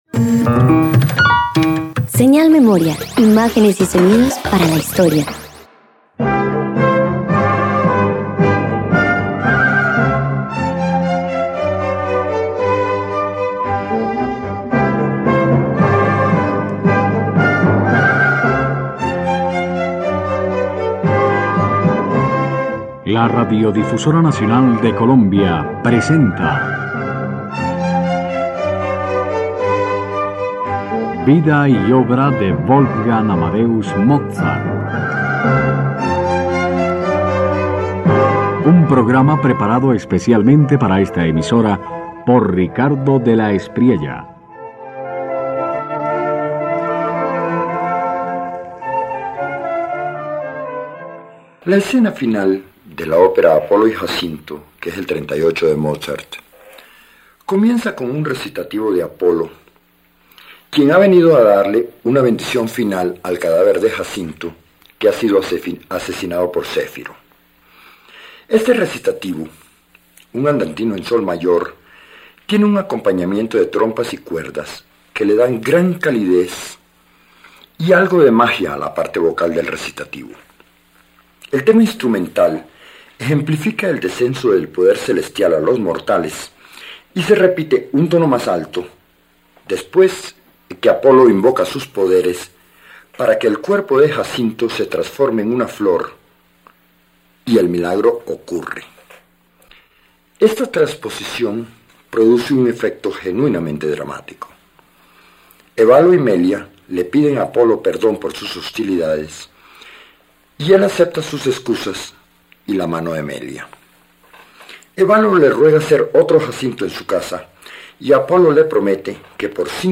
018 Apolo y Jacinto parte  III -  Sonatas para órgano y cuerdas 2.mp3